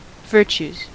Ääntäminen
Ääntäminen US Tuntematon aksentti: IPA : /ˈvəː.tʃuːs/ Haettu sana löytyi näillä lähdekielillä: englanti Käännöksiä ei löytynyt valitulle kohdekielelle.